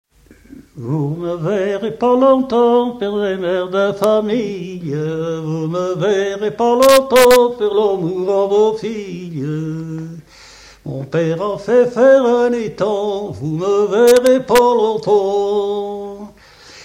Chant de conscrits pour le voyage
Genre strophique